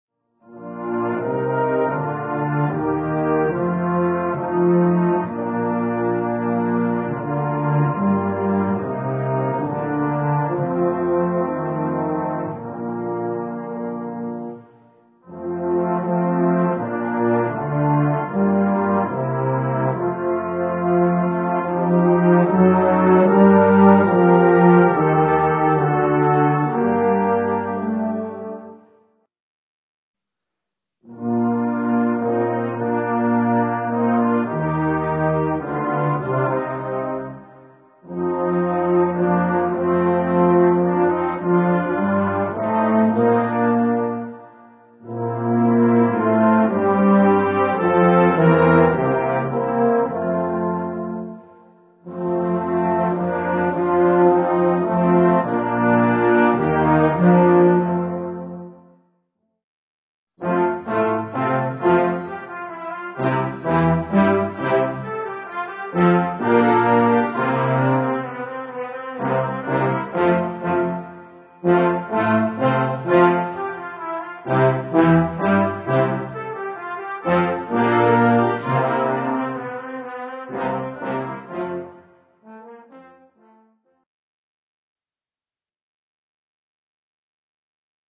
Gattung: Konzertante Blasmusik
Besetzung: Blasorchester